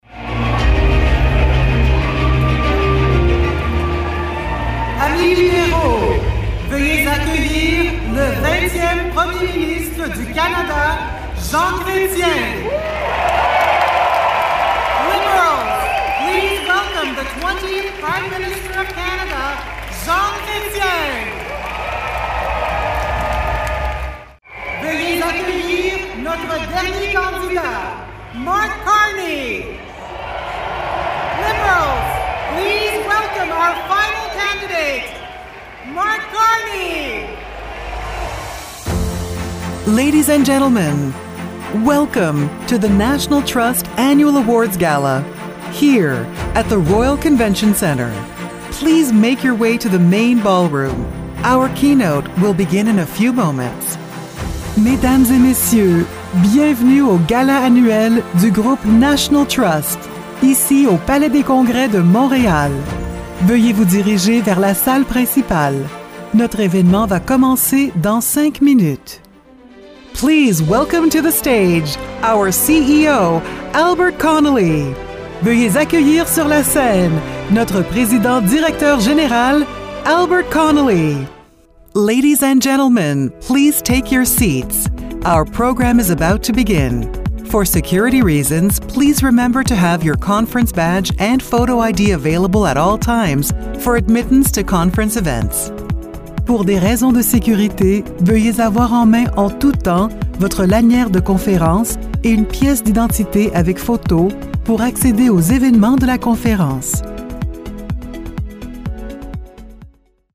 Actor de doblaje de Inglés (Estados Unidos)